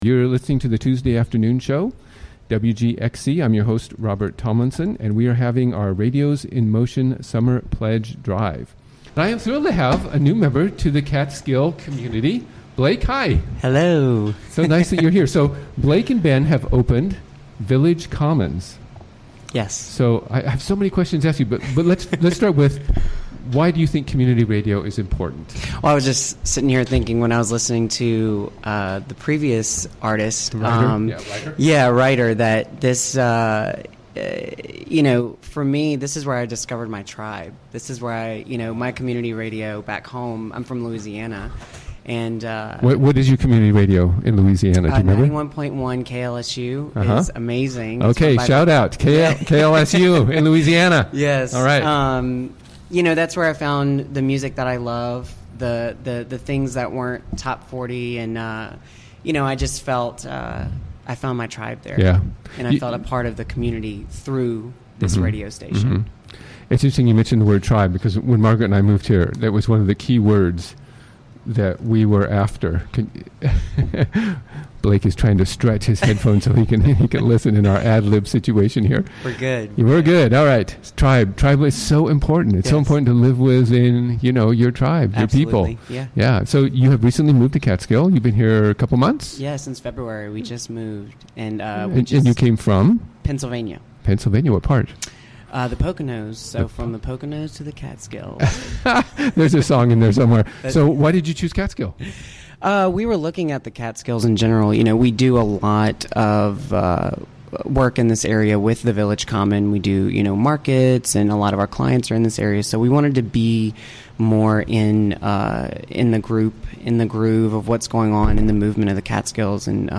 Interviewed
Recorded during the WGXC Afternoon Show of Tuesday, June 13, 2017.